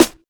Snares
Fun_Snr.wav